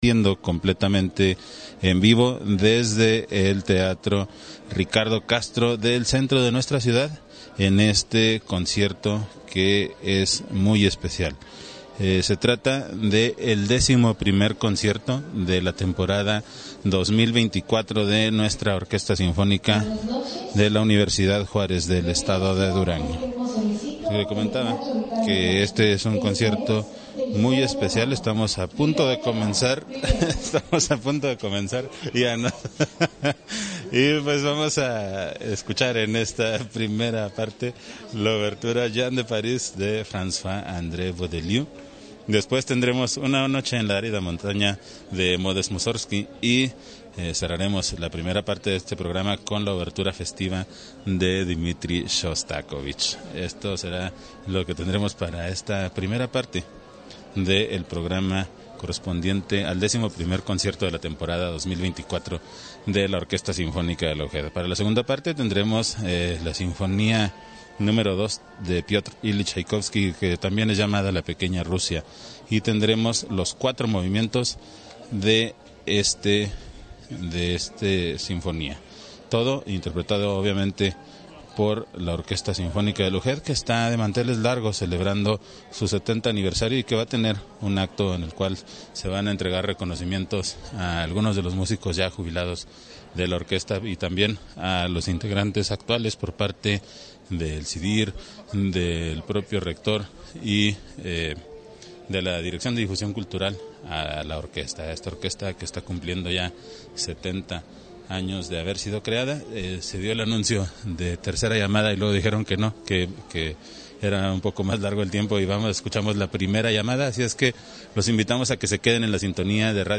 Concierto para vibráfono
El coro infantil